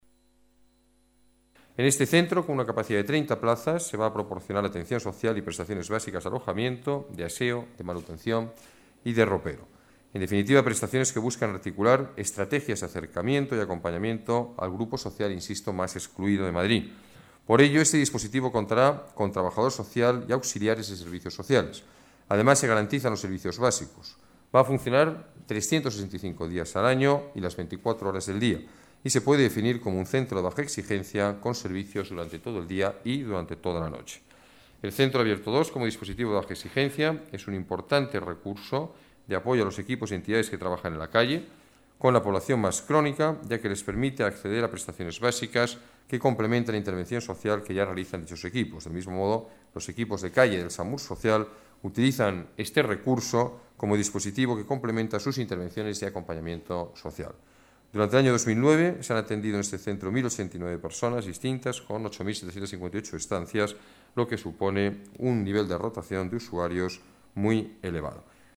Nueva ventana:Declaraciones del alcalde, Alberto Ruiz-Gallardón, sobre la atención a personas sin hogar